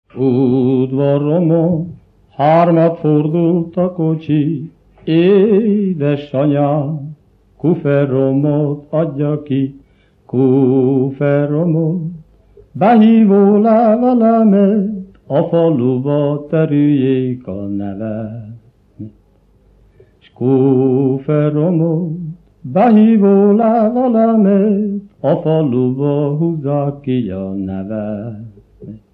Erdély - Csík vm. - Ugrapataka (Gyimesfelsőlok)
Műfaj: Katonadal
Stílus: 3. Pszalmodizáló stílusú dallamok
Kadencia: 4 (b3) 7 1